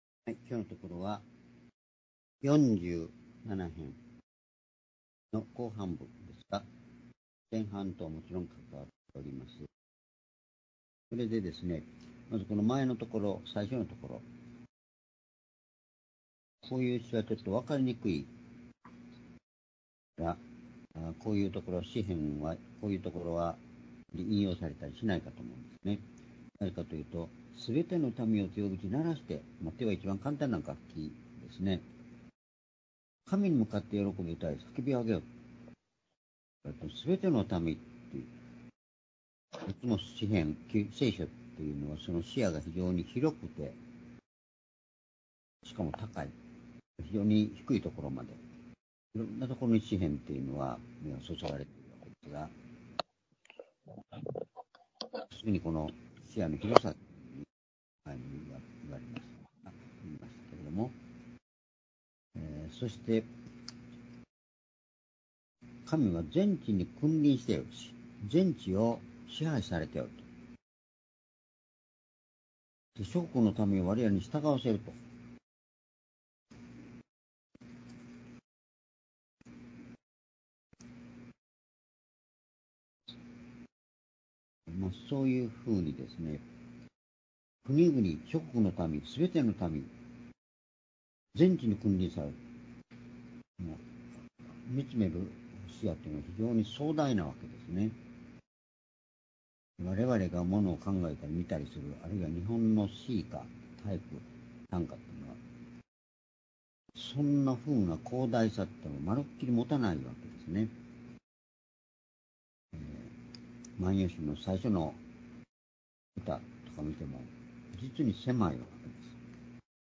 （主日・夕拝）礼拝日時 2025年3月4日(夕拝) 聖書講話箇所 「主への賛美と詩編」詩 編47編6～10節 ※視聴できない場合は をクリックしてください。